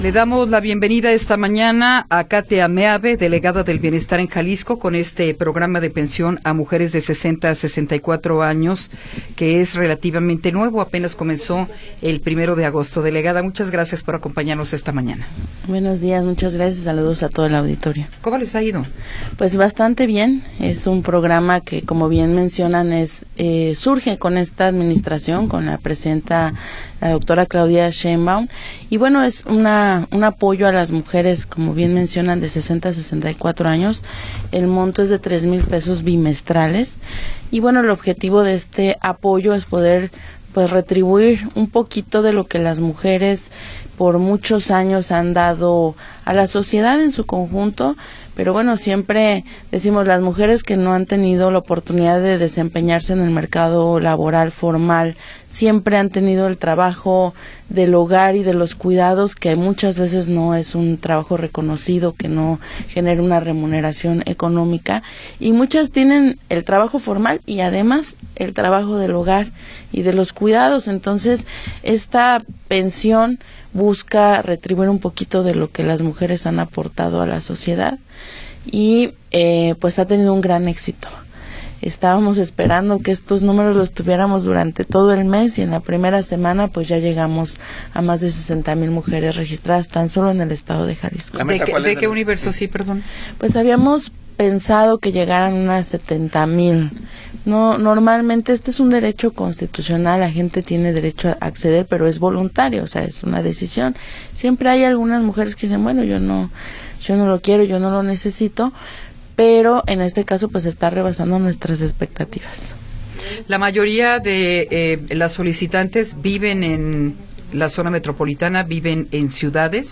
Entrevista con Katia Meave Ferniza